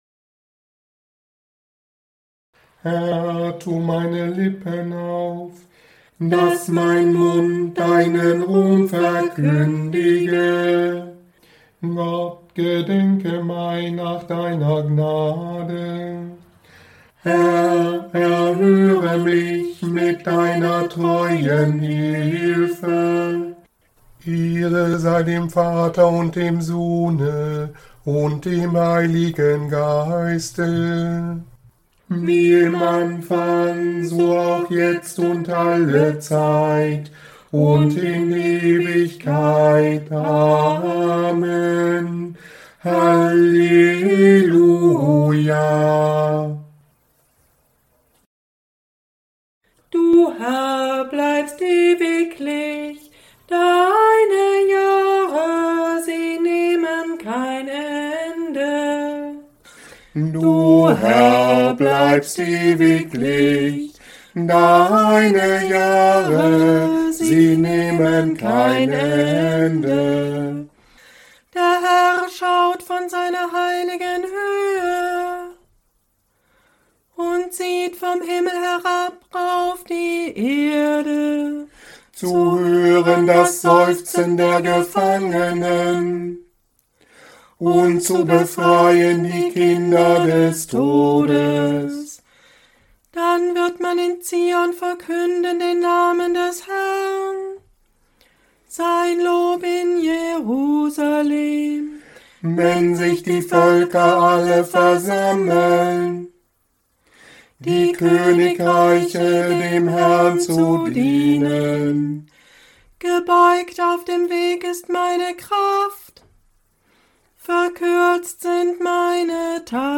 Morgengebet am Montag nach dem 1. Sonntag nach dem Christfest (29. Dezember) Nummern im Tagzeitenbuch: 330, 401, 404, 409, 410, 411+227, Raum zum persönlichen Gebet, 8